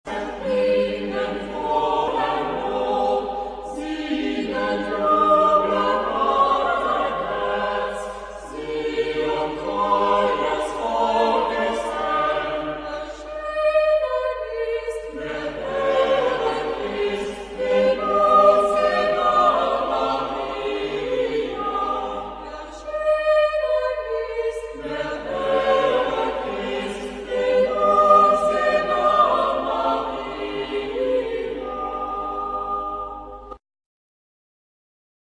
Epoque: 19th century
Genre-Style-Form: Sacred ; Canticle
Type of Choir: SATB  (4 mixed voices )
Tonality: G major